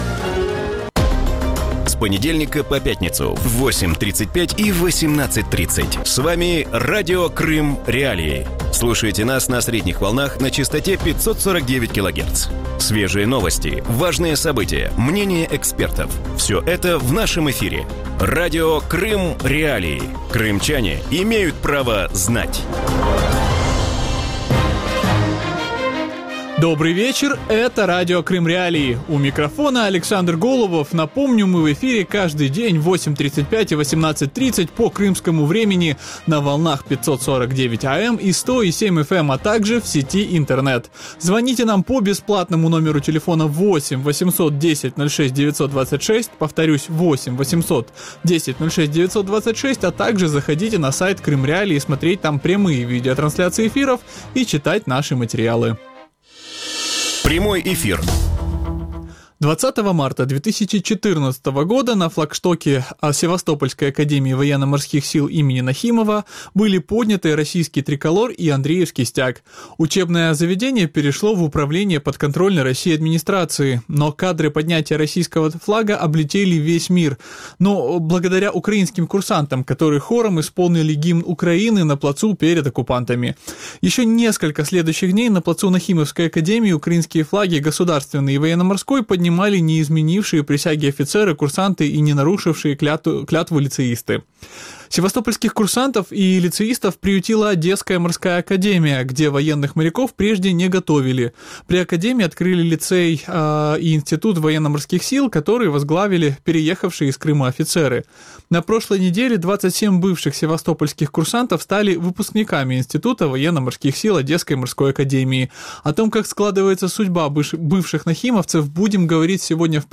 Радио Крым.Реалии выходит по будням в 8:35 и 18:30 на средних волнах АМ – частота 549 кГц, в FM-диапазоне на частоте 100.7 МГц, а также на сайте Крым.Реалии. Крымчане